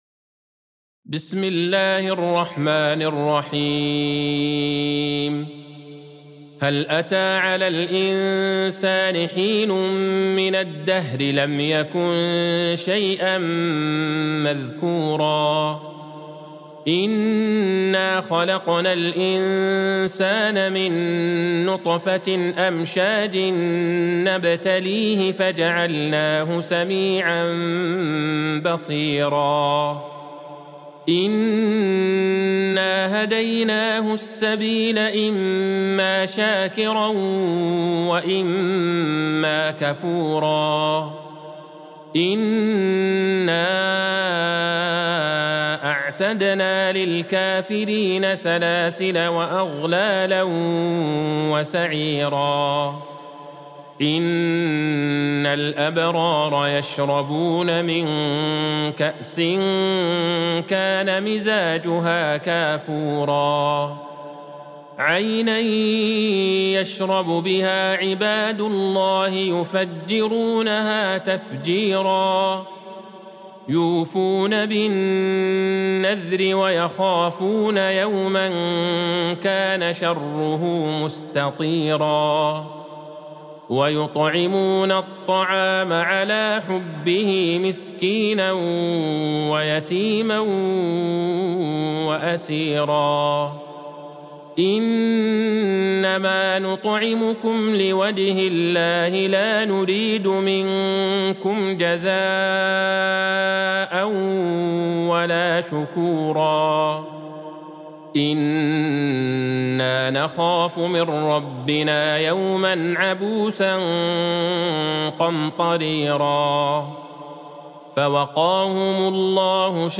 سورة الإنسان Surat Al-Insane | مصحف المقارئ القرآنية > الختمة المرتلة ( مصحف المقارئ القرآنية) للشيخ عبدالله البعيجان > المصحف - تلاوات الحرمين